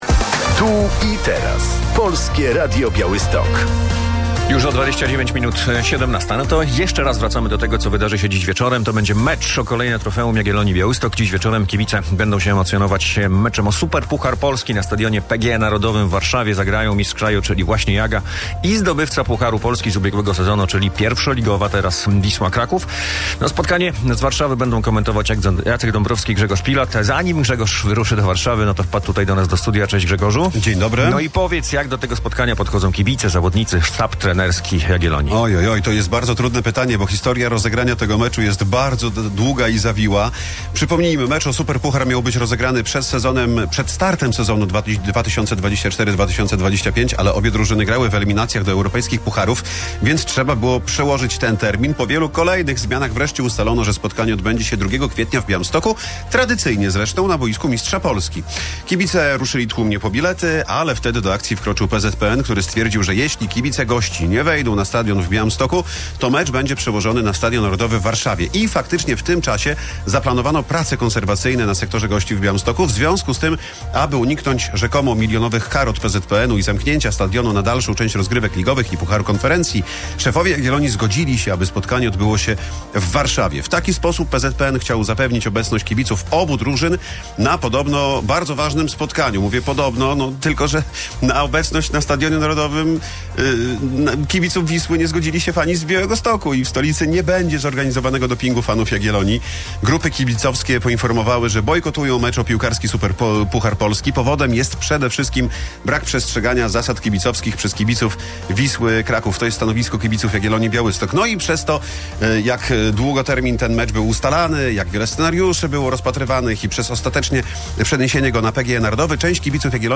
Żółto-czerwoni powalczą z Wisłą Kraków o Superpuchar Polski - relacja